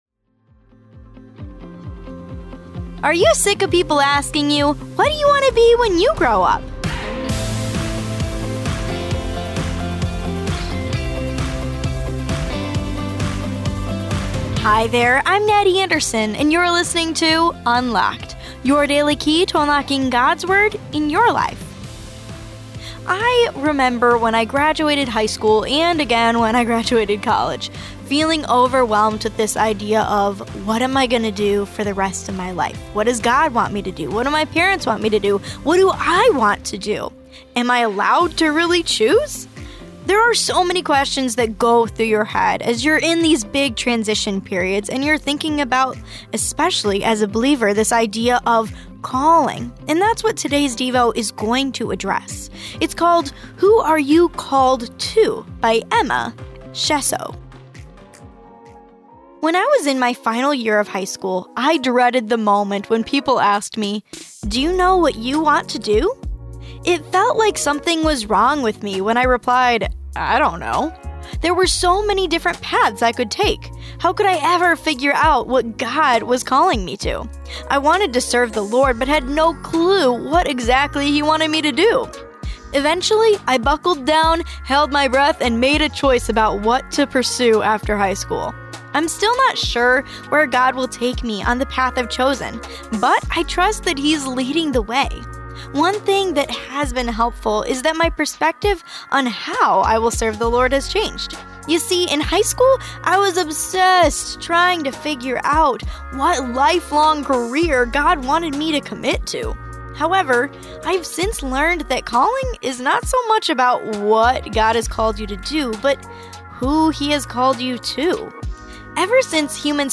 With daily devotions read by our hosts